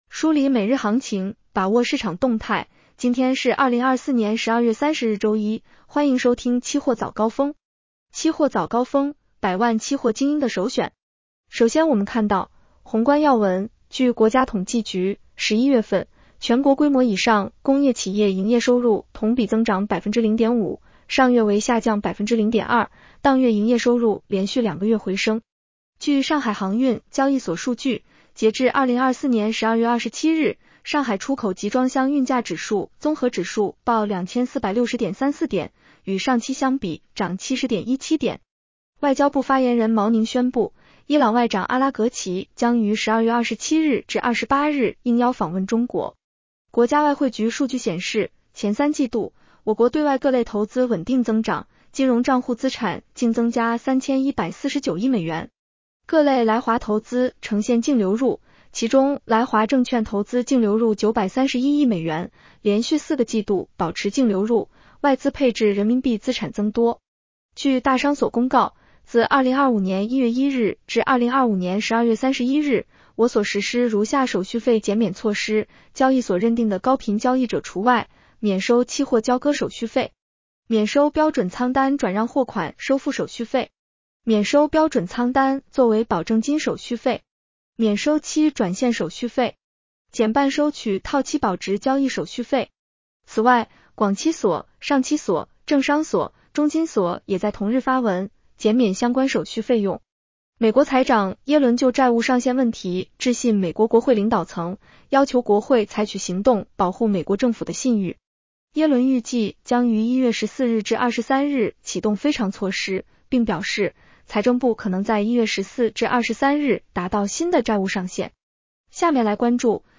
期货早高峰-音频版 女声普通&#…